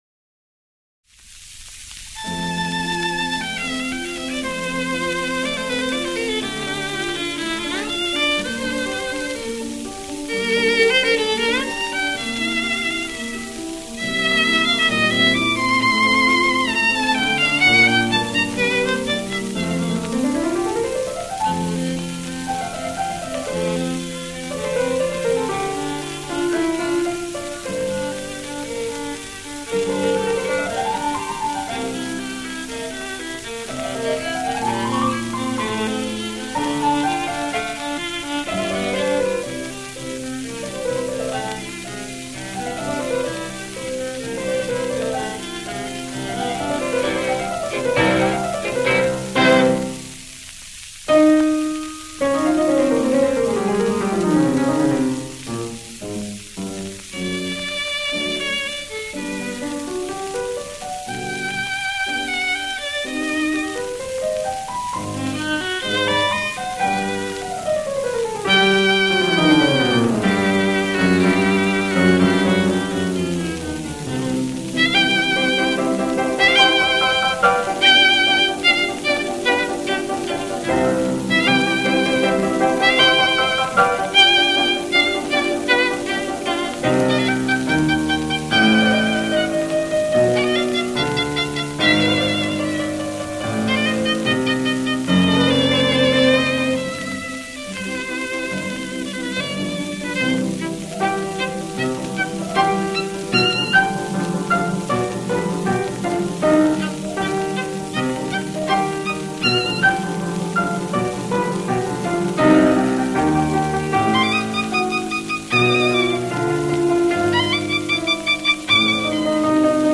录音年代：1936年